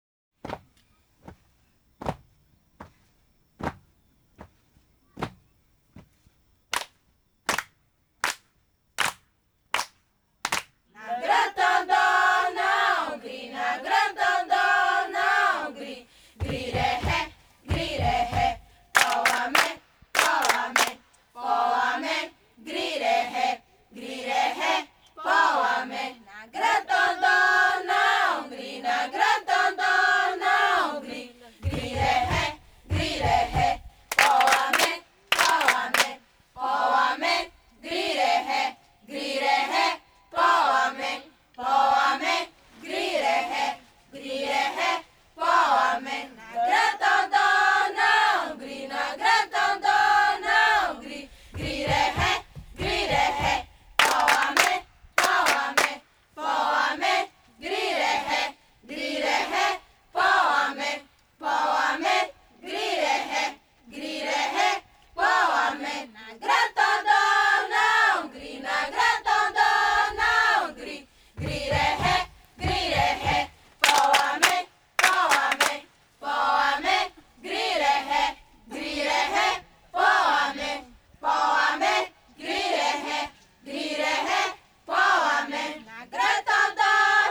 Ouça a canção Po Hamek, cantada pelas crianças do povo Krenak: